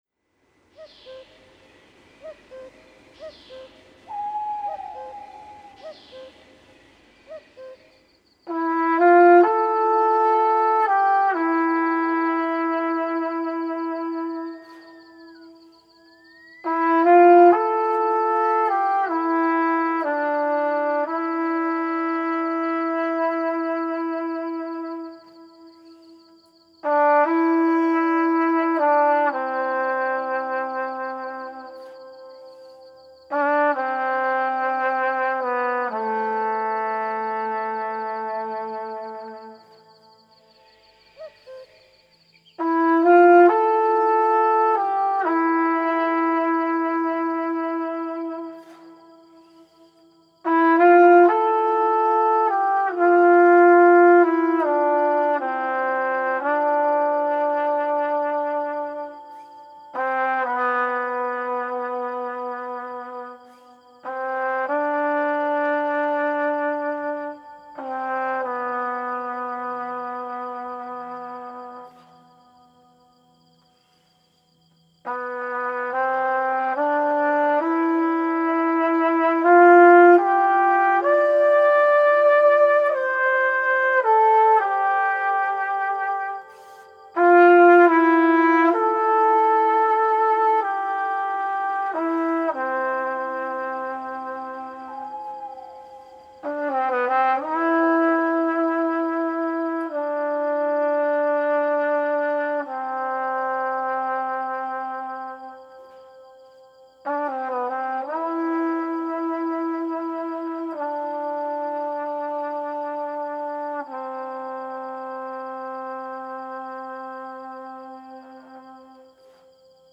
compositions musicales expressives